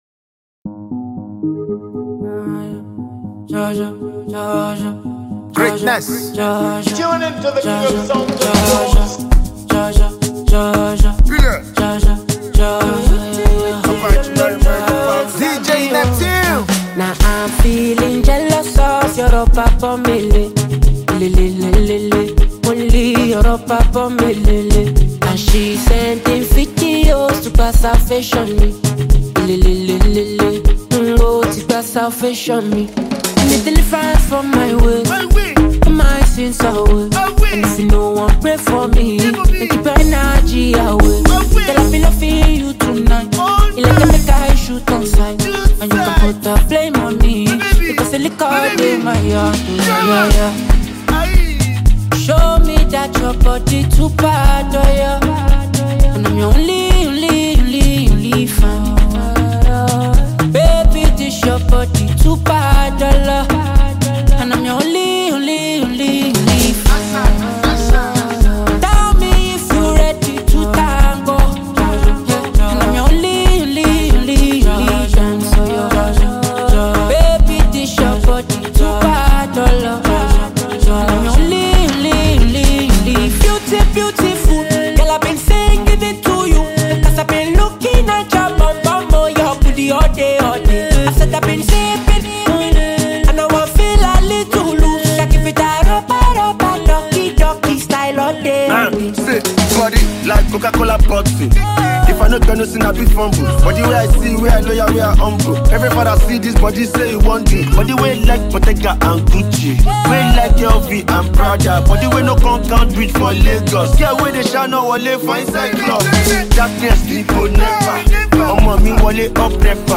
Afrobeats
Street-pop rapper